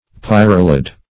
Pyralid \Pyr"a*lid\, n. [L. pyralis, -idis, a kind of winged